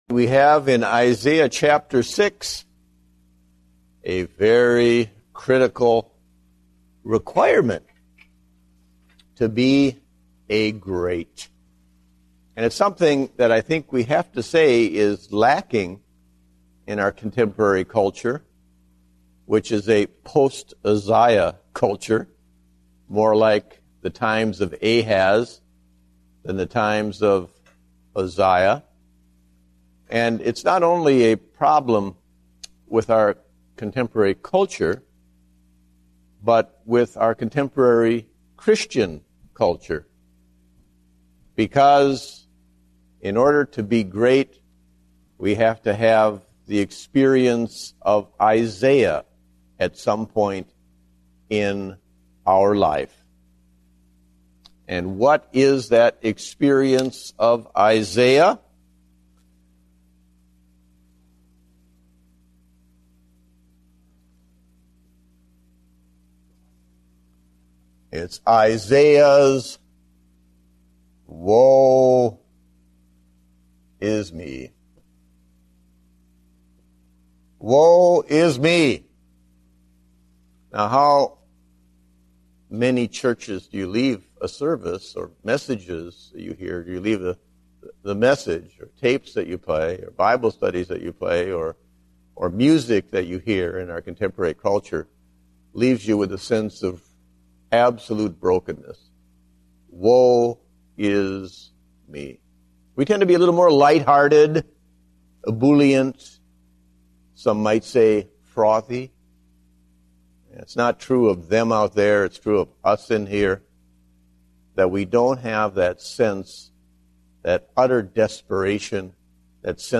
Date: January 10, 2010 (Adult Sunday School)